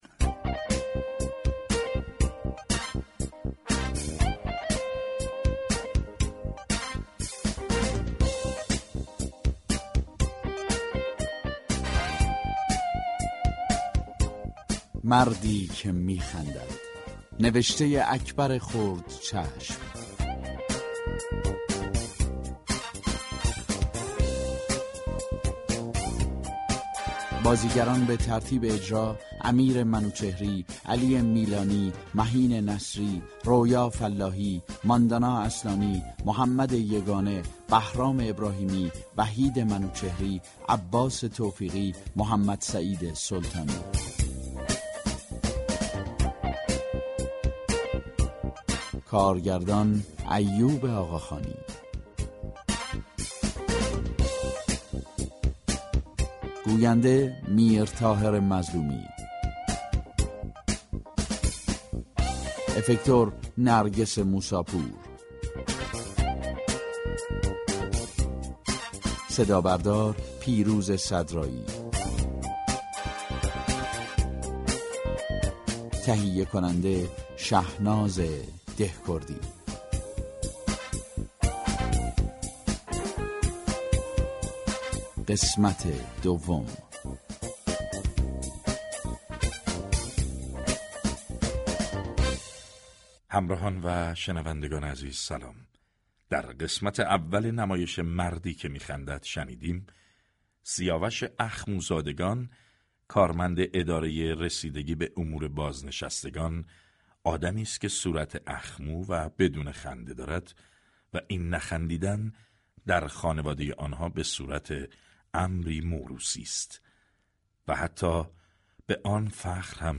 چهارم شهریور ماه ، سریال رادیویی